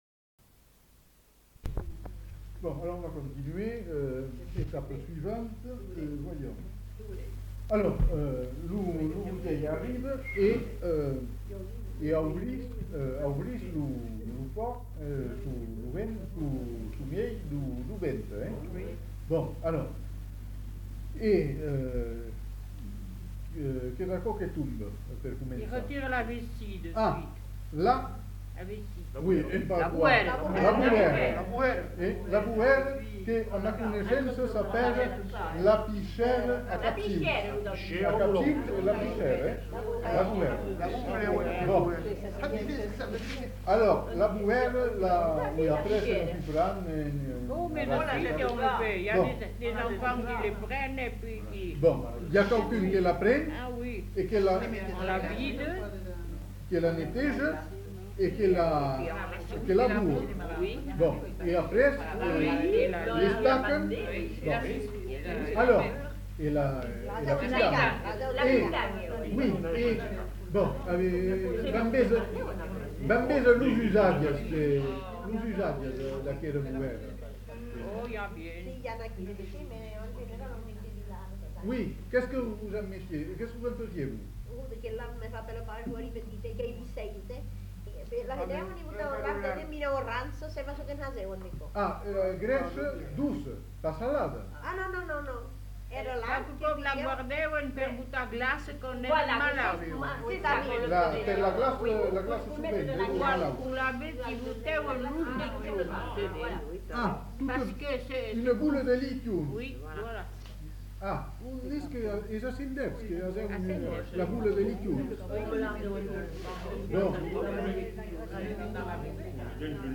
Lieu : Bazas
Genre : témoignage thématique
archives sonores en ligne Contenu dans [enquêtes sonores] La cuisine du cochon dans la région de Bazas